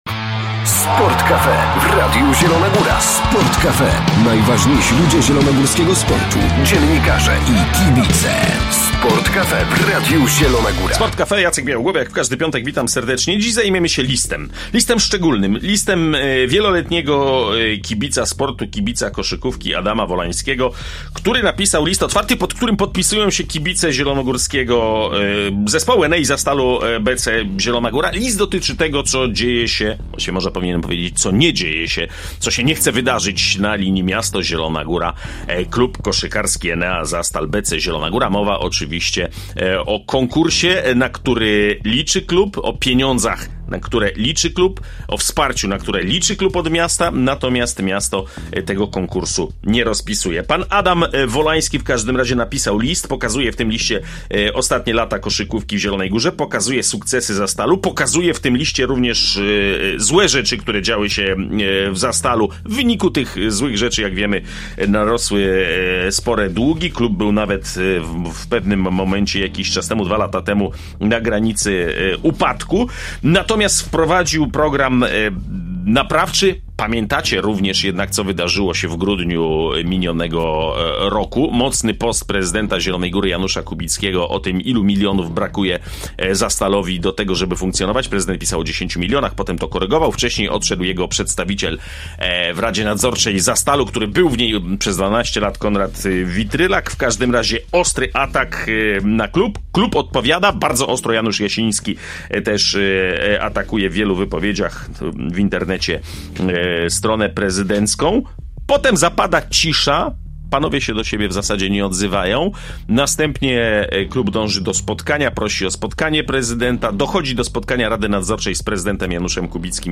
W Sport cafe rozmawiamy dzisiaj z wieloletnim kibicem sportu, a w szczególności koszykówki i zielonogórskiego Zastalu o liście, który napisał